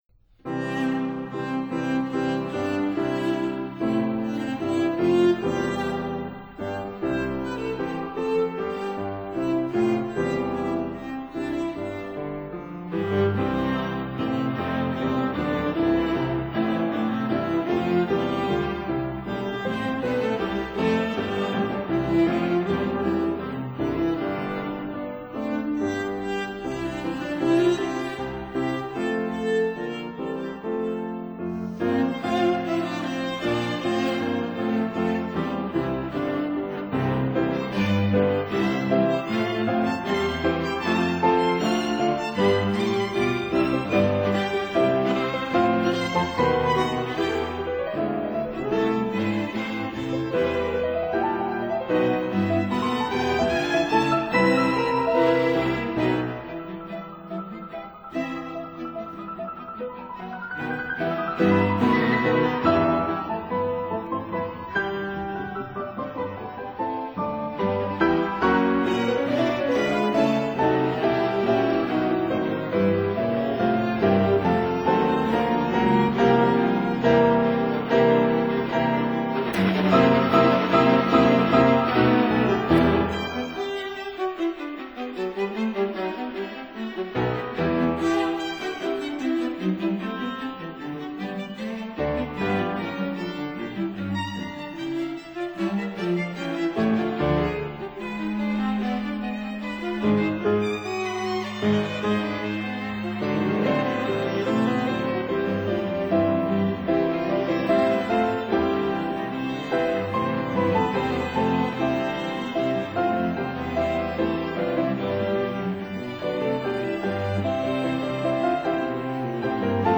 violin
viola
cello
double bass
piano